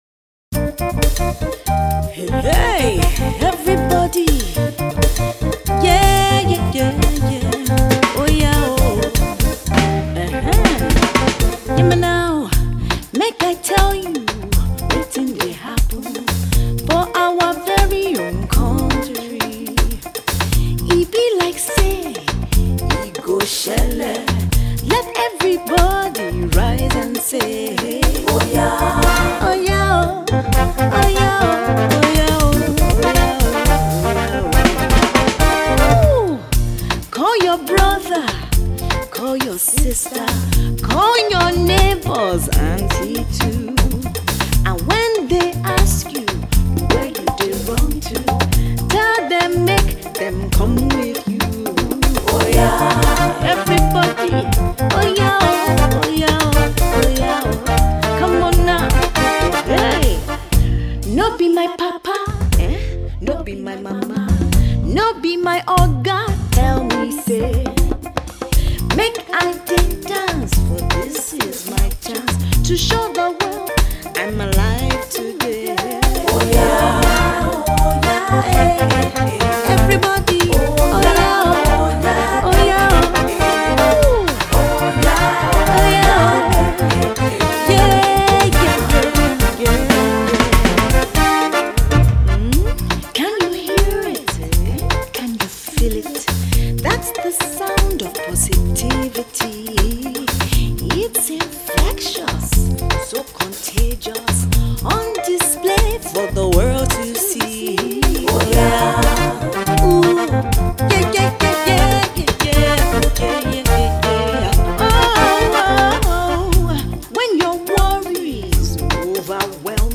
Afro Pop/Soul songstress
very funky Afro-High Life track